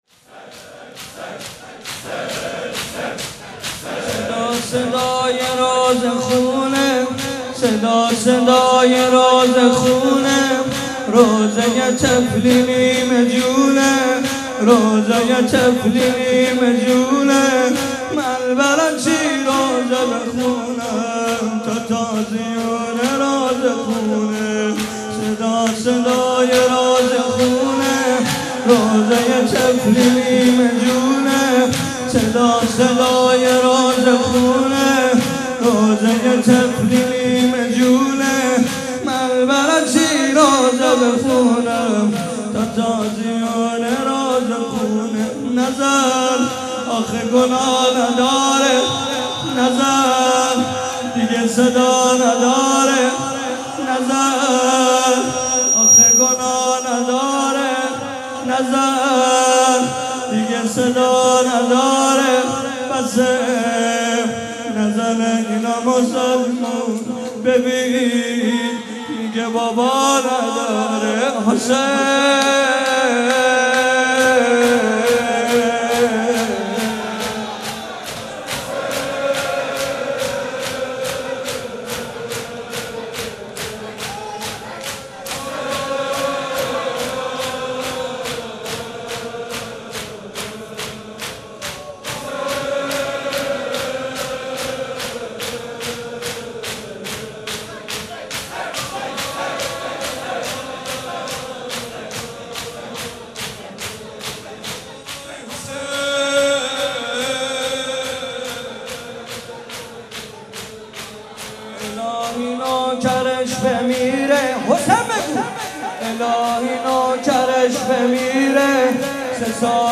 مناسبت : شب بیست و سوم رمضان - شب قدر سوم
مداح : محمدرضا طاهری قالب : شور